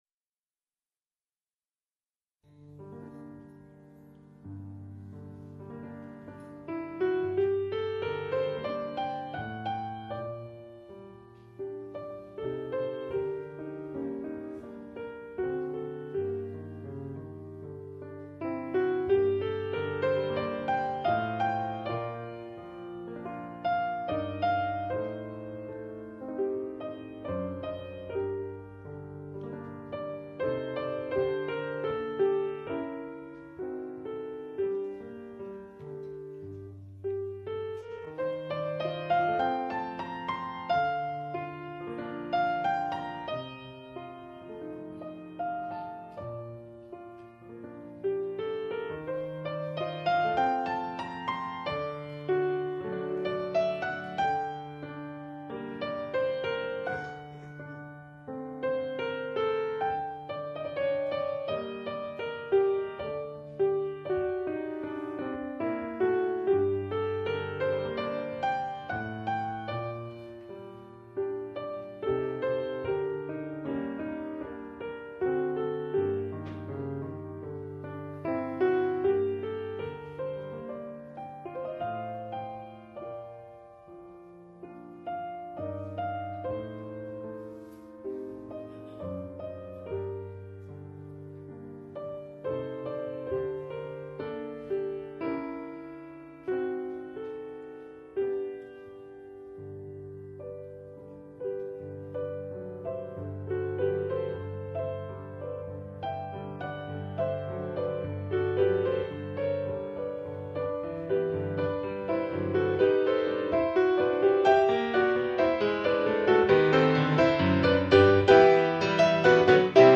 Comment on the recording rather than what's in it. Live Recording - Rome 1994